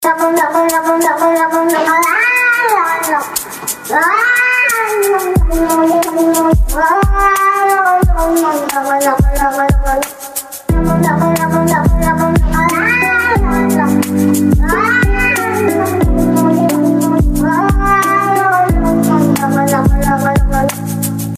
Kategorien Tierstimmen